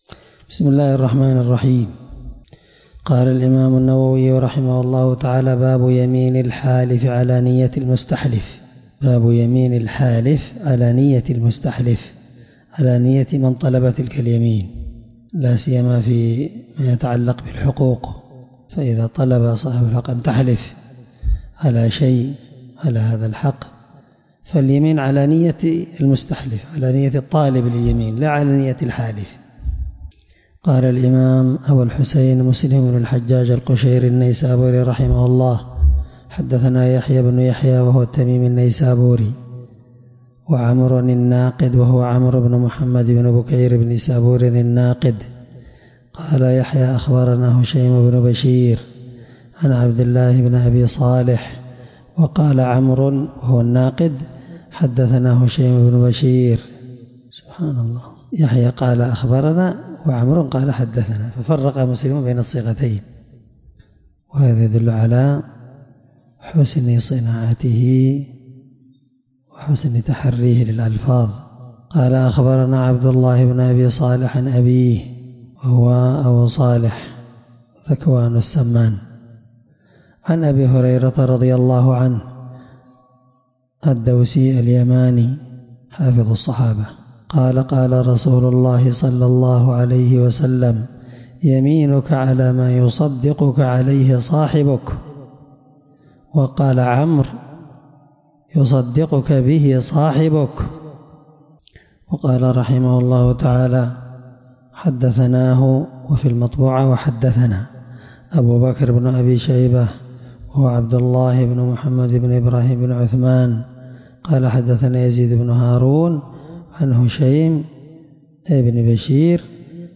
الدرس6من شرح كتاب الأيمان حديث رقم(1653) من صحيح مسلم